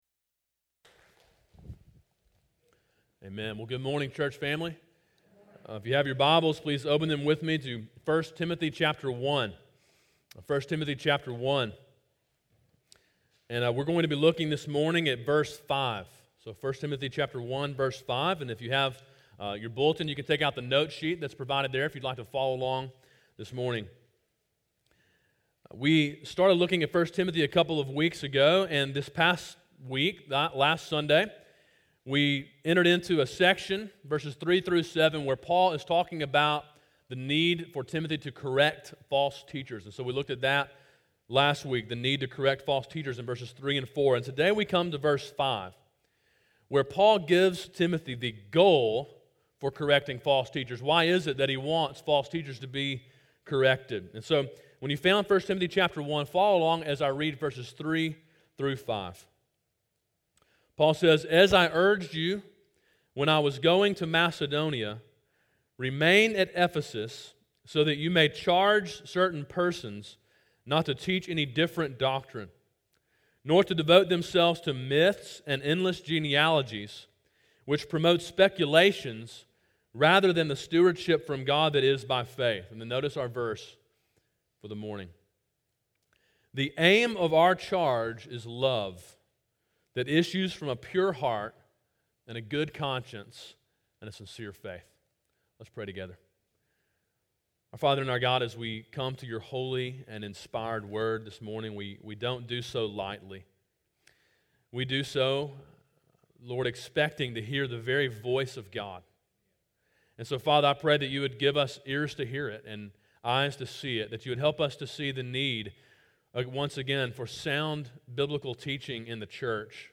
A sermon in a series through the book of 1 Timothy.